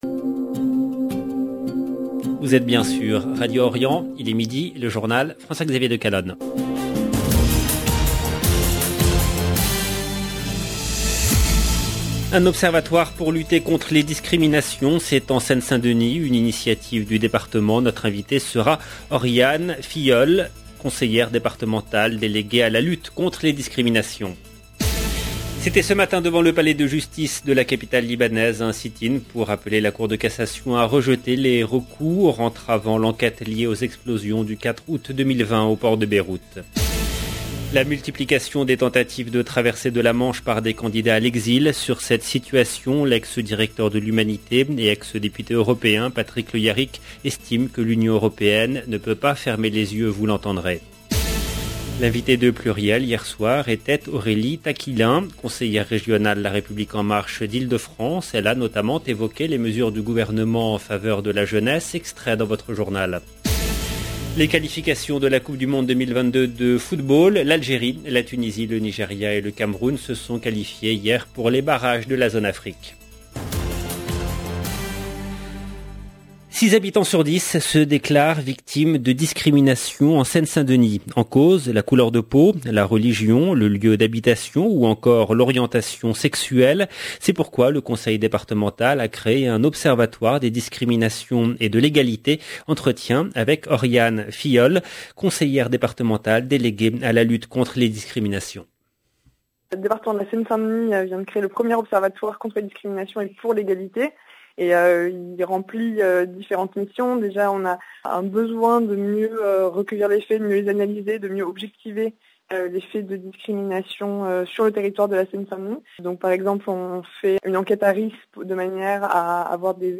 Notre invitée sera Oriane Filhol, conseillère départementale déléguée à la lutte contre les discriminations.